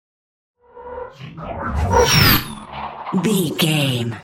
Horror whoosh electronic
Sound Effects
Atonal
tension
ominous
eerie